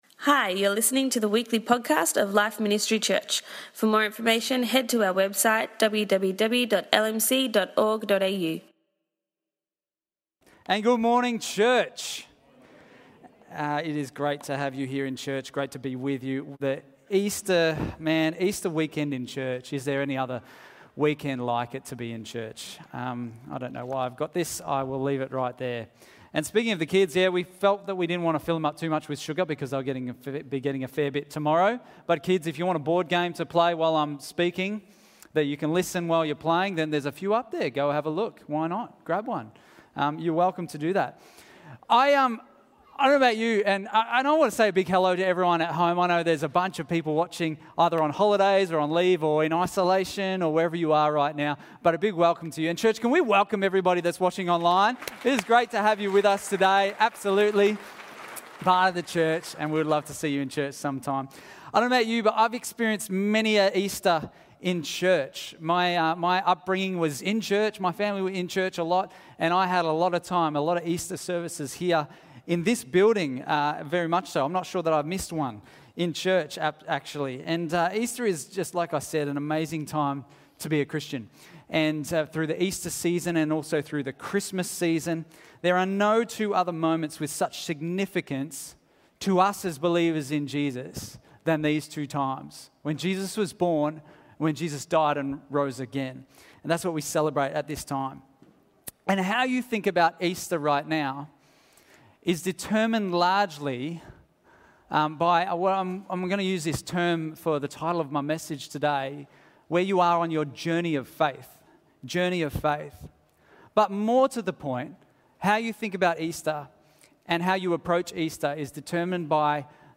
Good Friday 2022